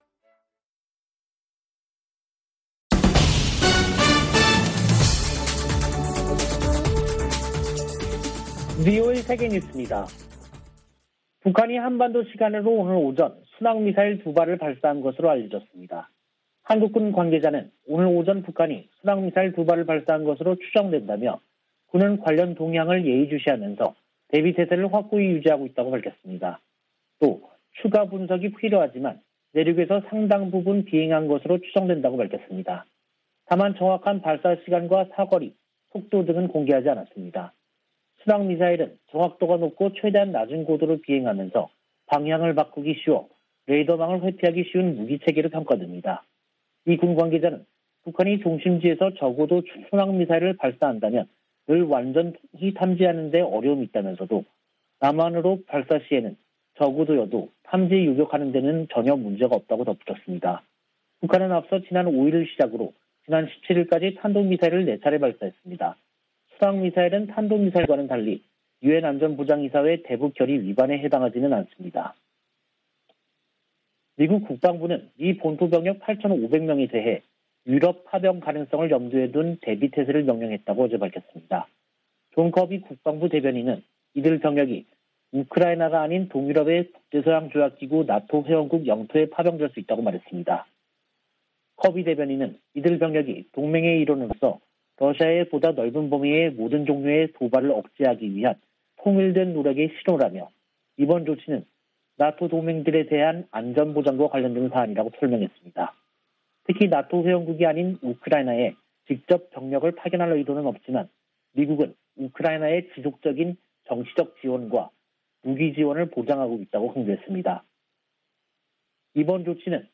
VOA 한국어 간판 뉴스 프로그램 '뉴스 투데이', 2022년 1월 25일 3부 방송입니다. 북한이 순항미사일로 추정되는 발사체 2발을 쏜 것으로 전해졌습니다.